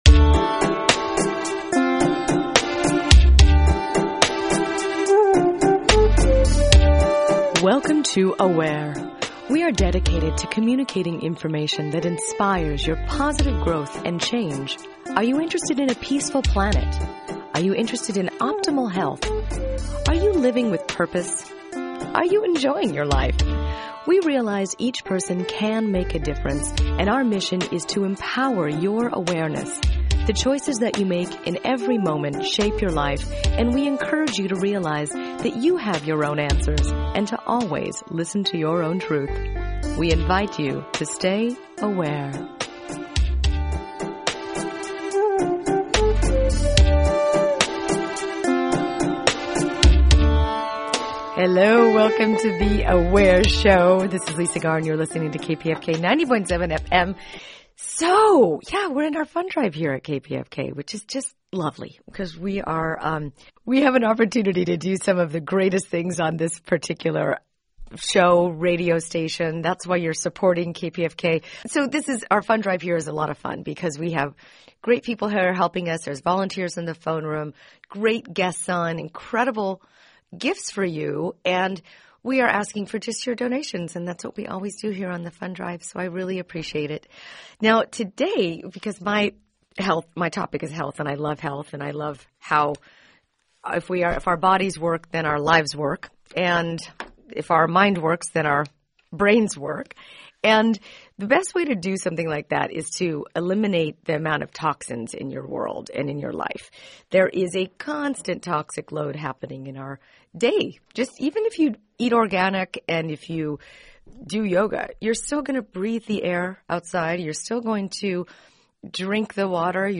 Tune into this important fund drive show!